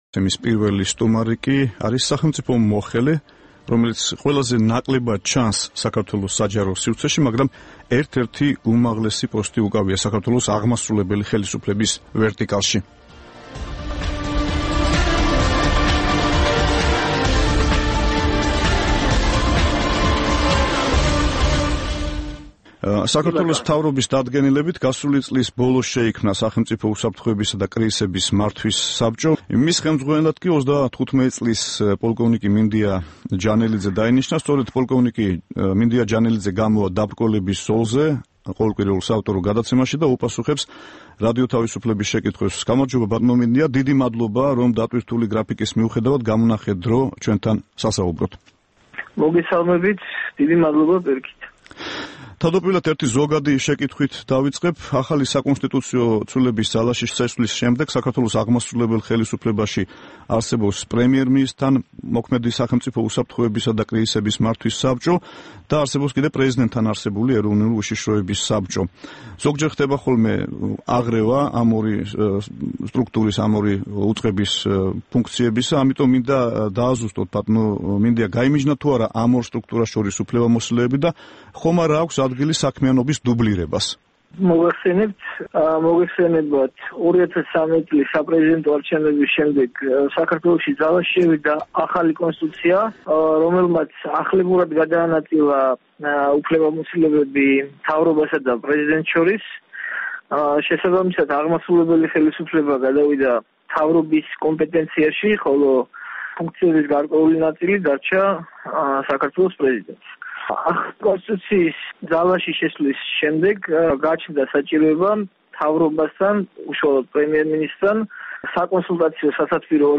საუბარი მინდია ჯანელიძესთან